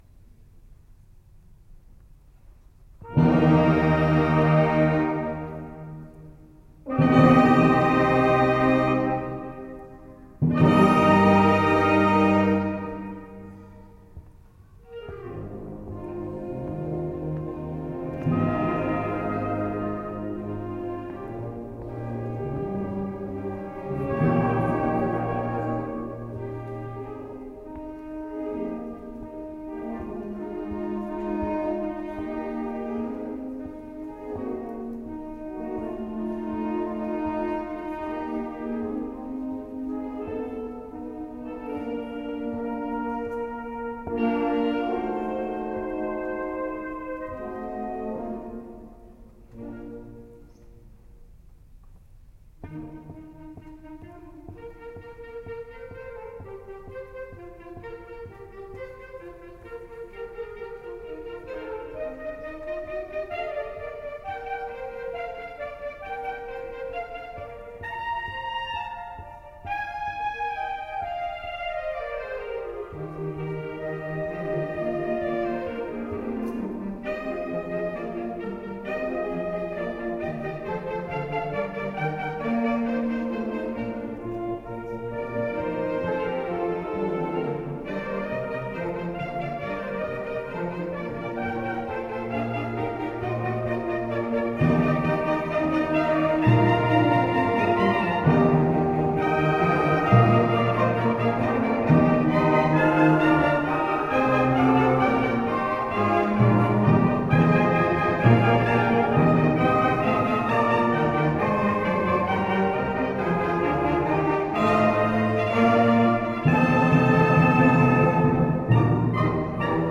BANDA MUSICALE
Concerto di Natale 2011